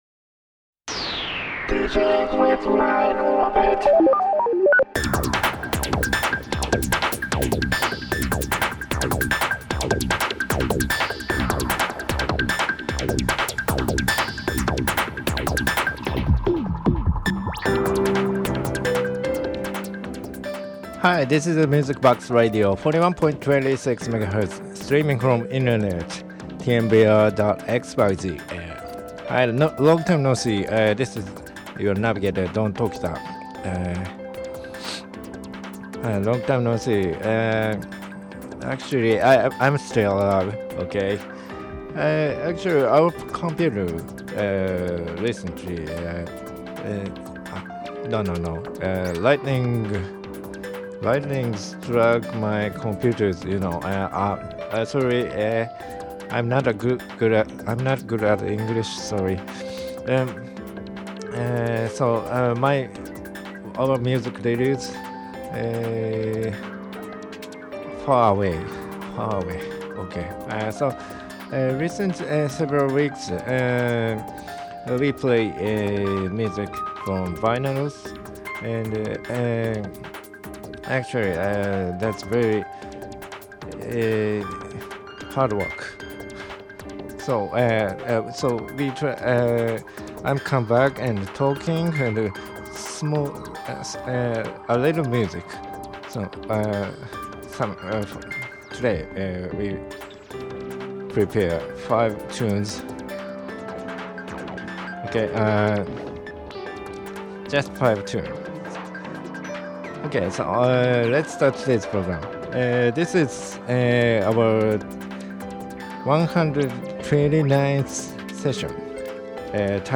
But we came to know that we need only DJ mixer.